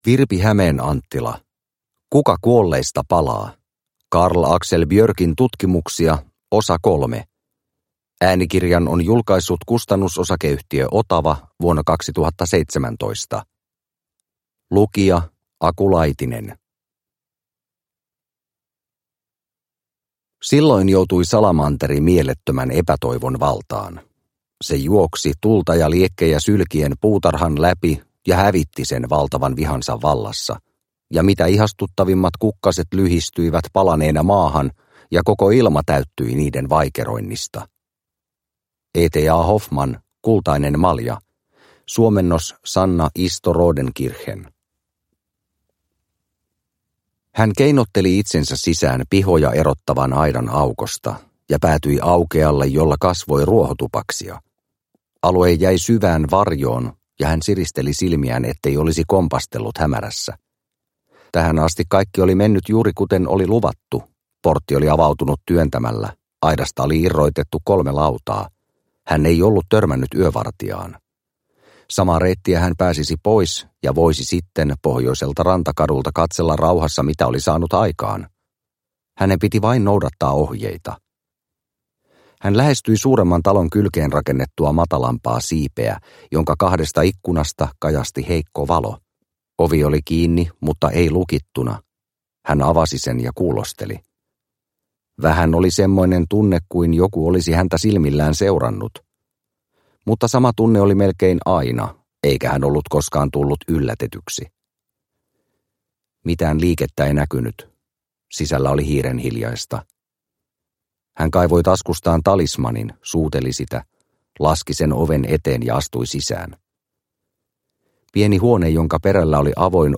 Kuka kuolleista palaa – Ljudbok – Laddas ner